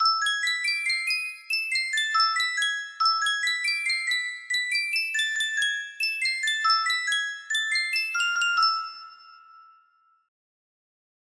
2 music box melody
Full range 60